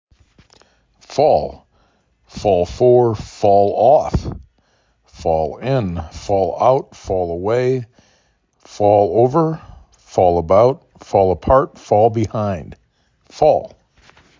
3 Phonemes
f aw l
f o l